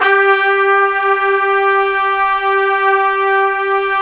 Index of /90_sSampleCDs/Roland LCDP06 Brass Sections/BRS_Tpts mp)f/BRS_Tps Swel %wh